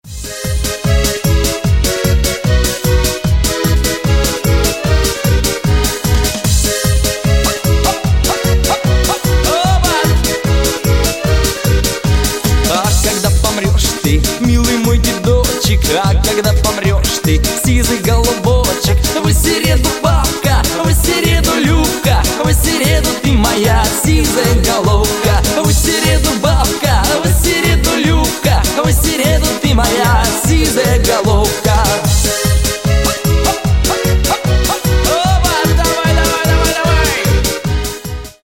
2022 » Русские » Поп Скачать припев